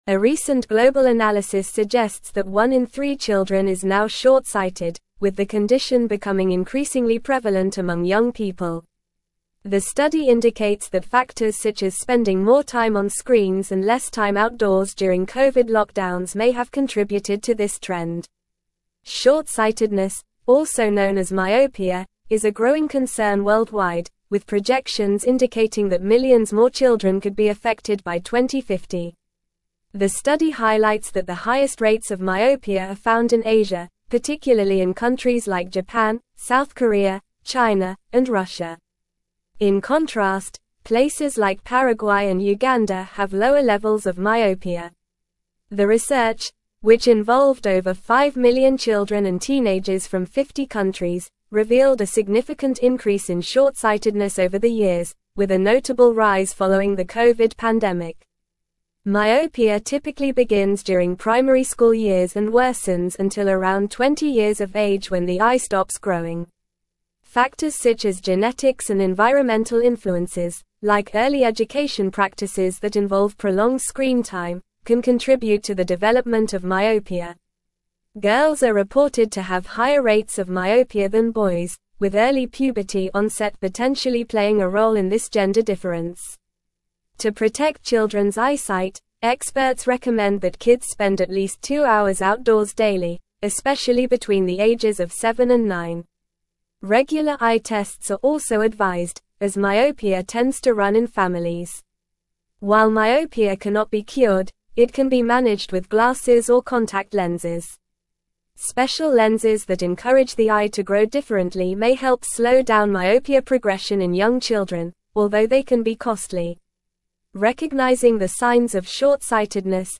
Normal
English-Newsroom-Advanced-NORMAL-Reading-Rising-Myopia-Rates-in-Children-Global-Concerns-and-Solutions.mp3